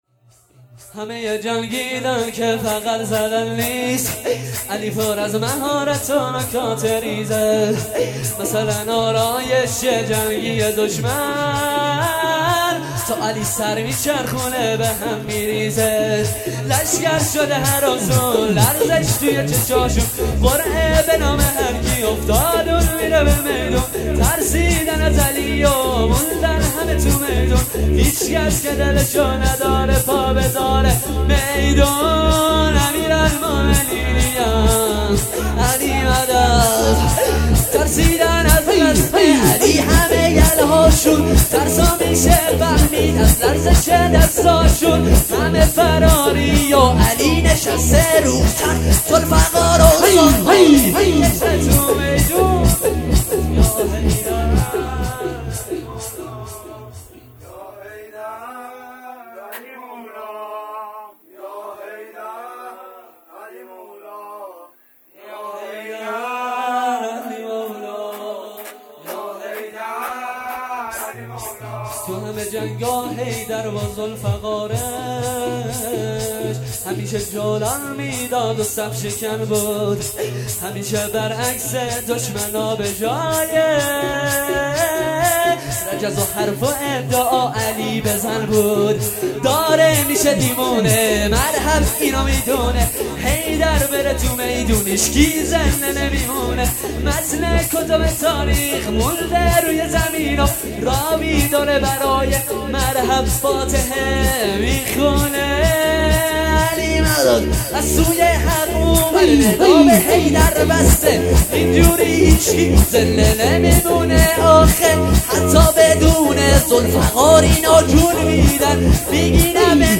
شور 2
تخریب قبور ائمه بقیع علیهم سلام الله 1402